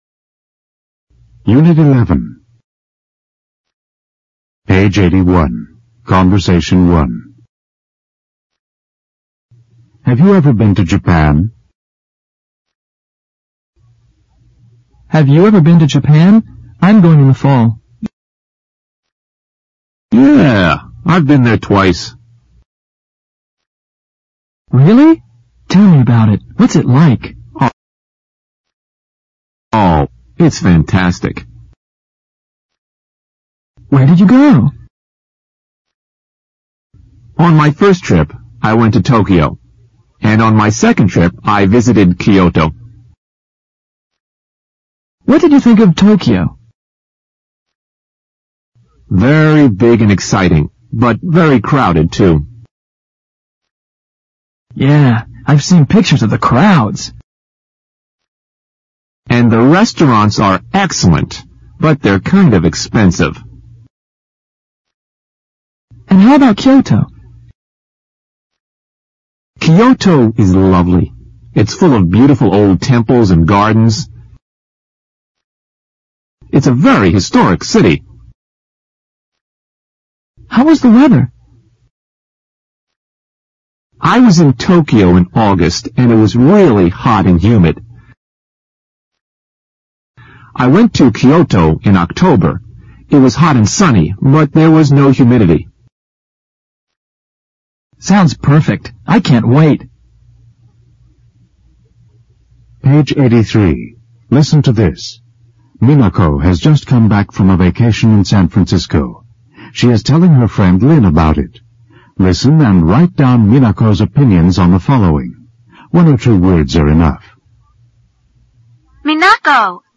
简单英语口语对话 unit11_conbersation1_new(mp3+lrc字幕)